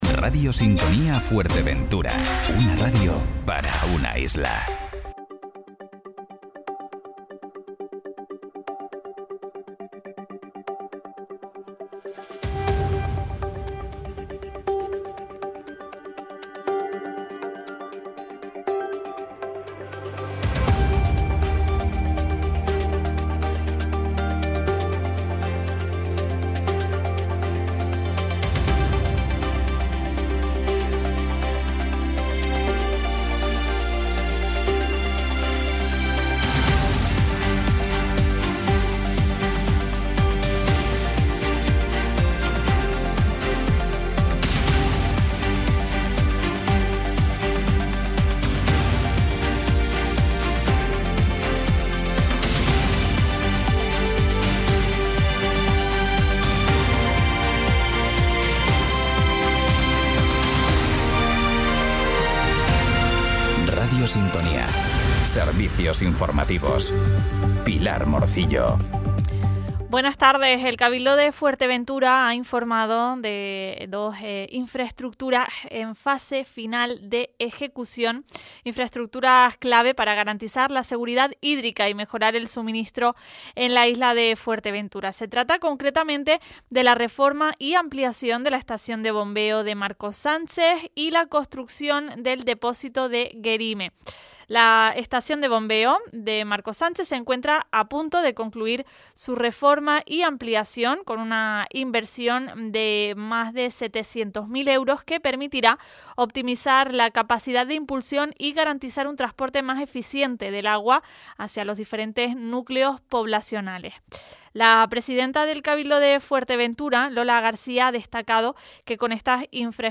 Servicios Informativos Informativos en Radio Sintonía - 23.09.25 Sep 23 2025 | 00:15:23 1x 00:00 / 00:15:23 Subscribe Share RSS Feed Share Link Embed